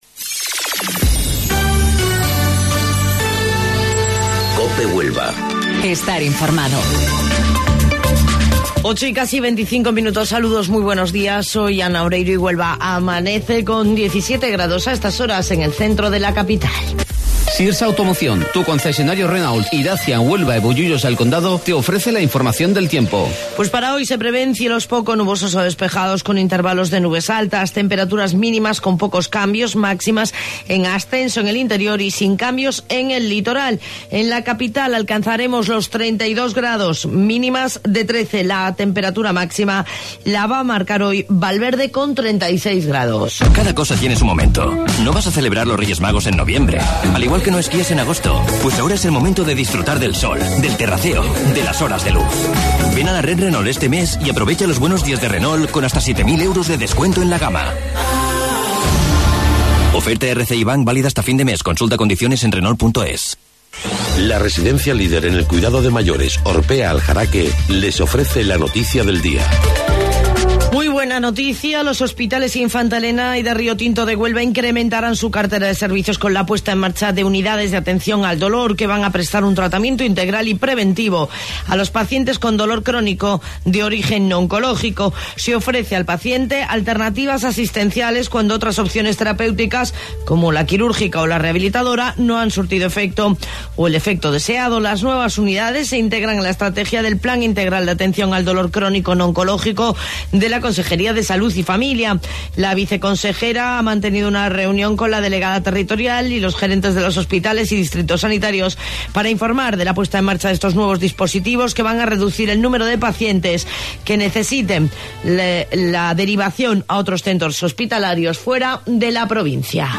AUDIO: Informativo Local 08:25 del 15 de Mayo